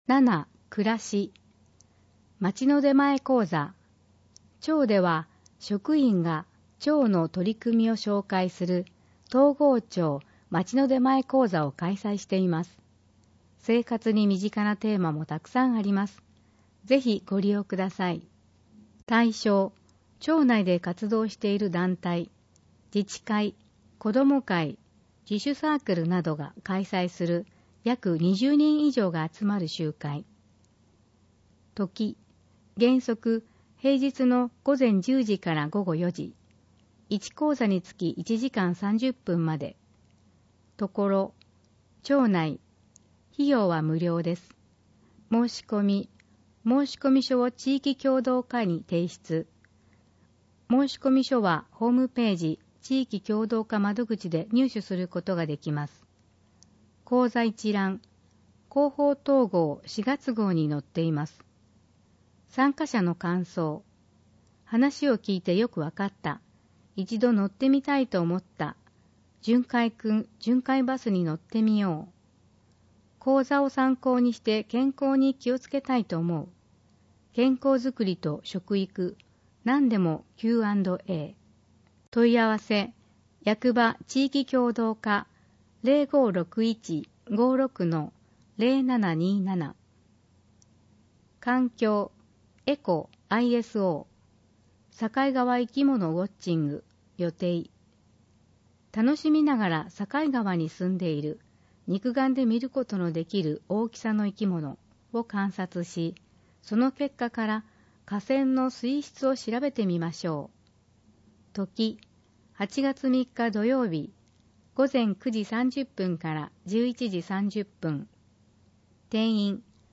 生涯学習情報誌ジョイフル音訳版（2019年春号）|東郷町